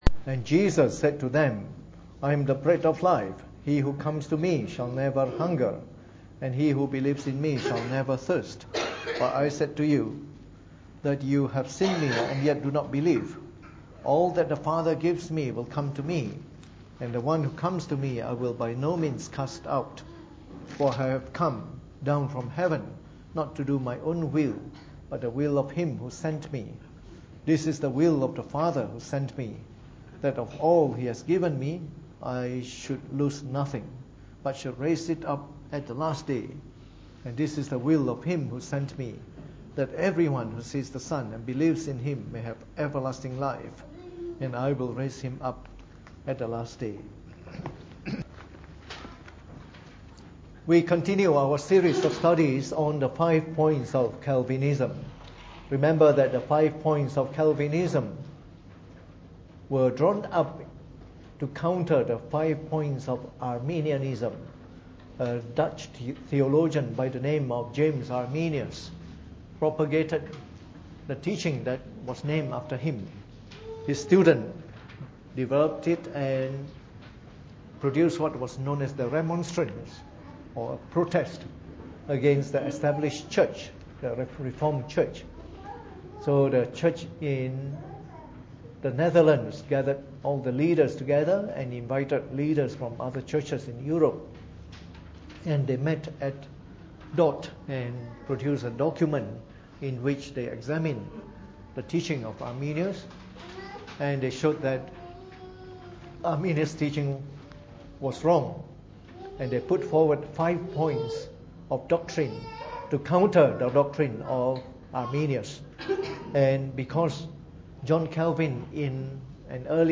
Preached on the 21st of September 2016 during the Bible Study, from our series on the Five Points of Calvinism.